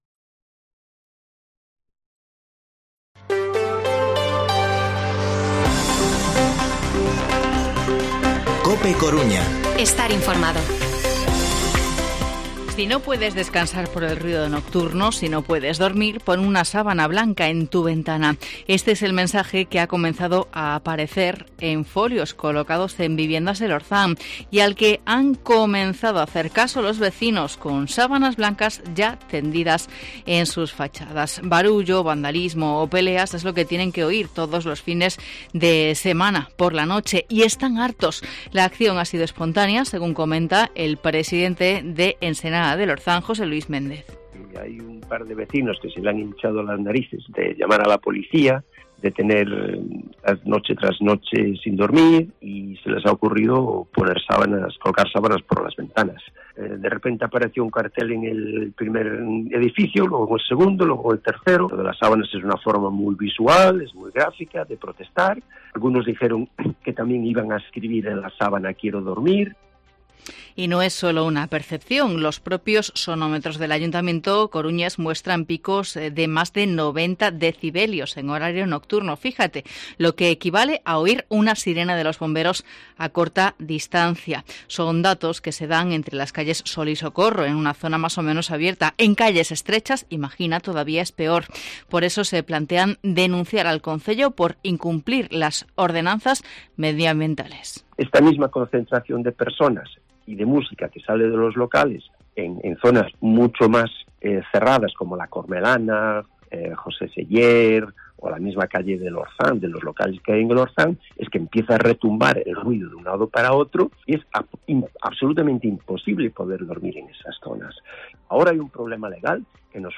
Informativo Mediodía COPE Coruña martes, 2 de noviembre de 2021 14:20-14:30